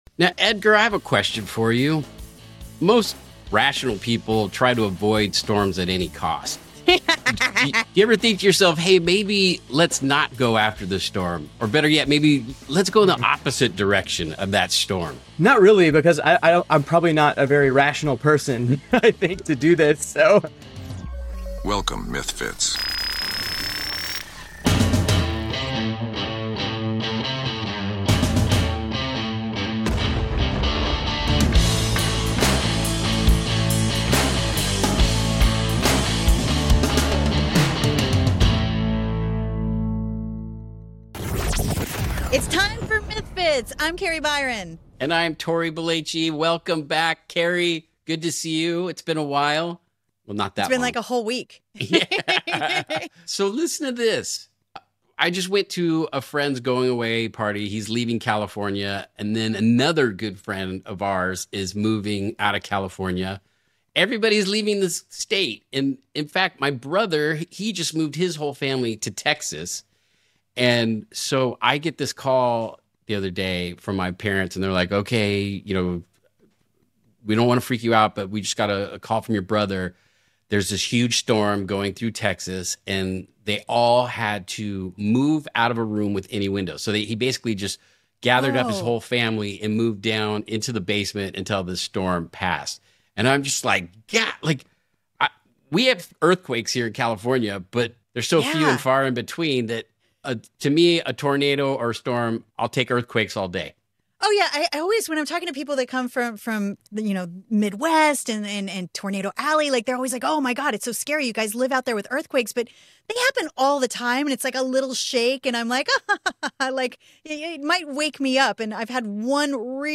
Tory and Kari talk to a lightning strike survivor with his story on surviving a lightning strike.